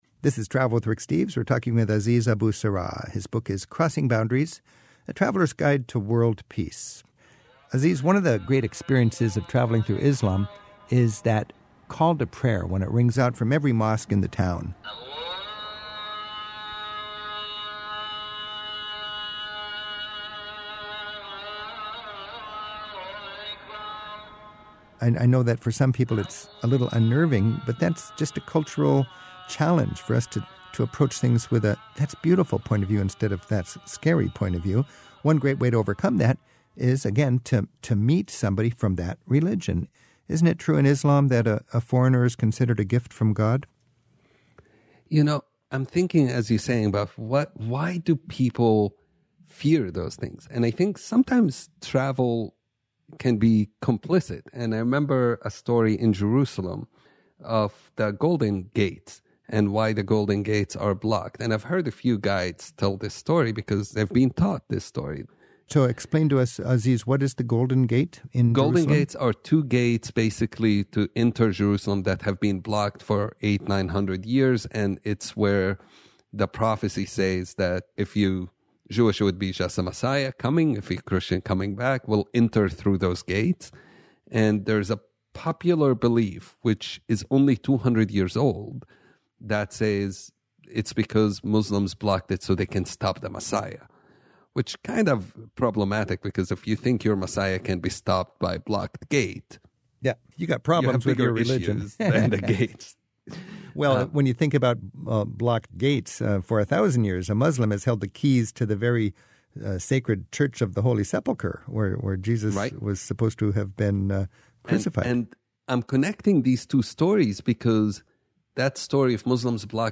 Peace activist